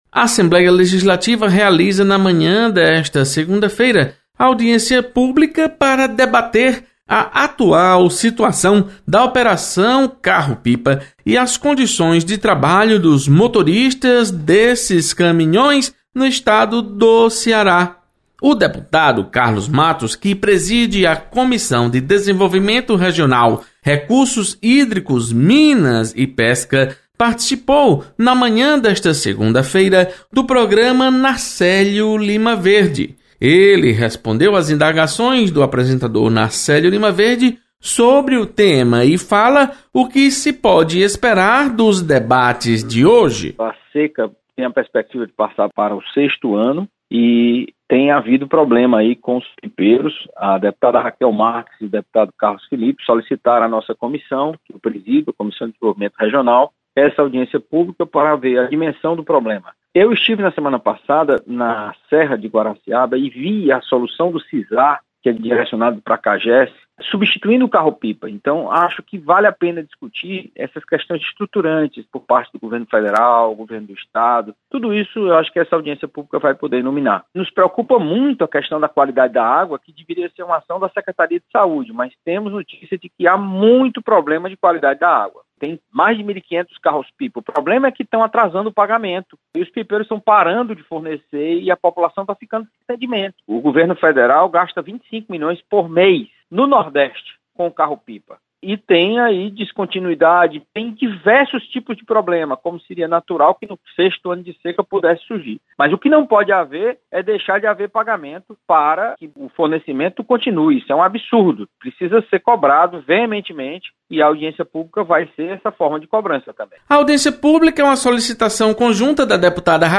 Entrevista